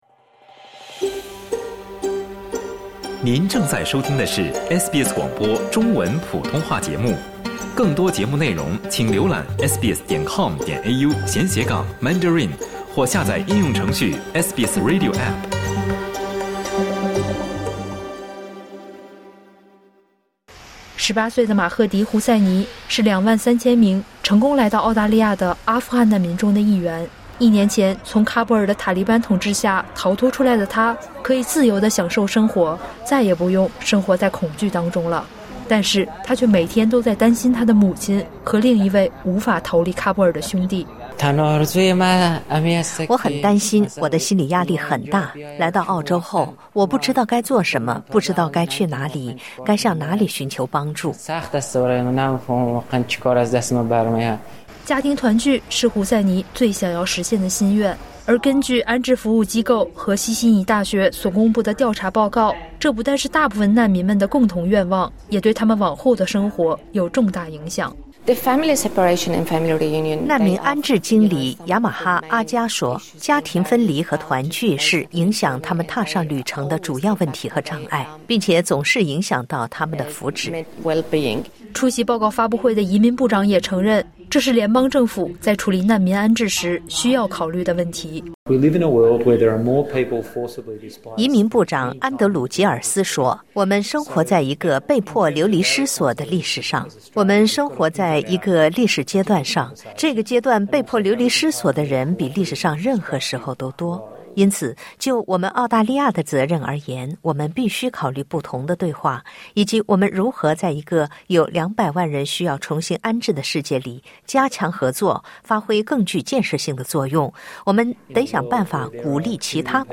阿富汗口译员 03:24 SBS 普通话电台 View Podcast Series Follow and Subscribe Apple Podcasts YouTube Spotify Download (3.12MB) Download the SBS Audio app Available on iOS and Android 一位已故澳大利亚士兵的父亲，第一次见到了儿子生前的阿富汗战友。